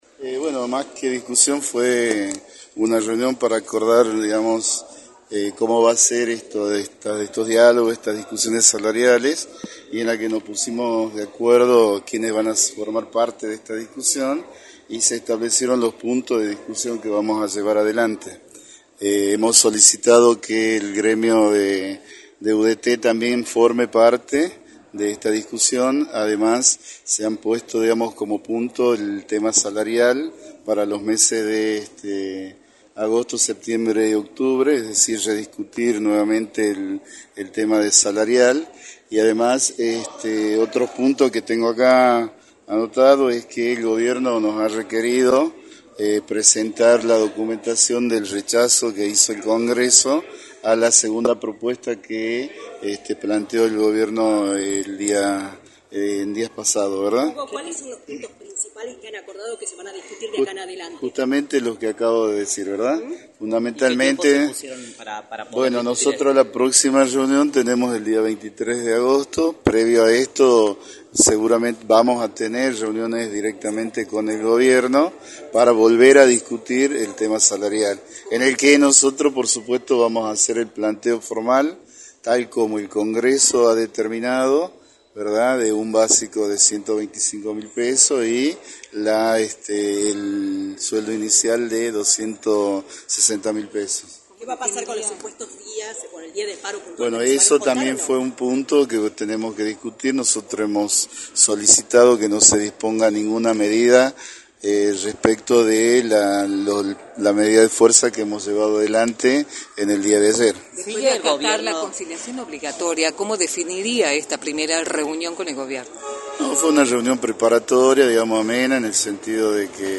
entrevista para «La Mañana del Plata» por la 93.9